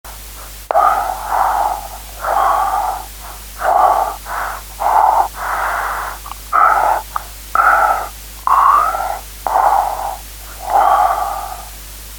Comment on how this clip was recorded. Â The constant air flow in and out because of breathing is modulated by your vocal cords in silent speaking when you think and it is the recording of this modulation that creates ‘speak thinking’.